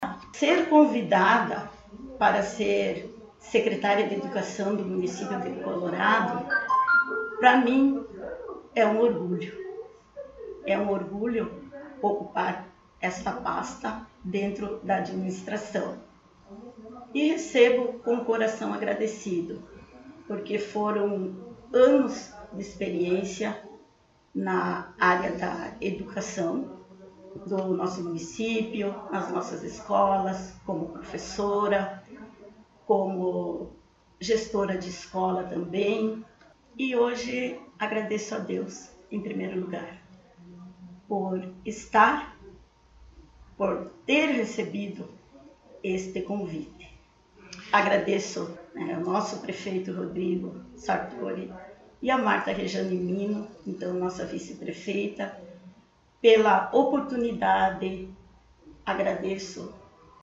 Secretária Municipal de Educação, Cultura e Desporto concedeu entrevista